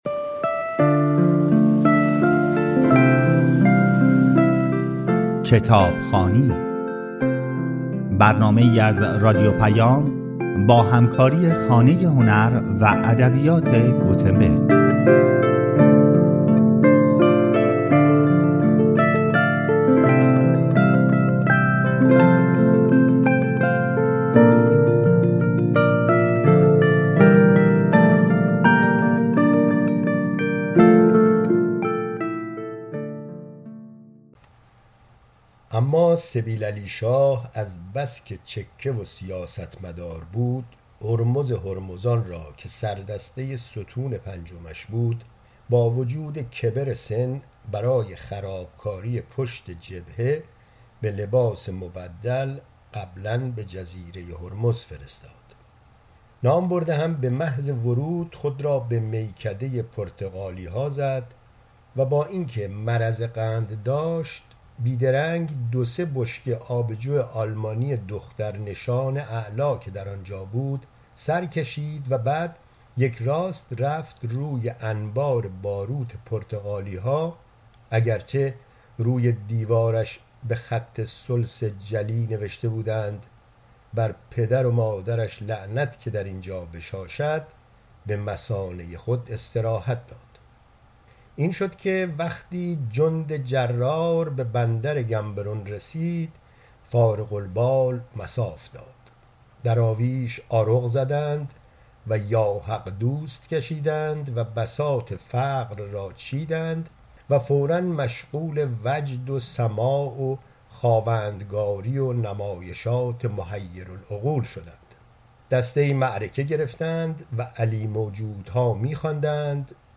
در برنامه کتابخوای روخوانی شده است.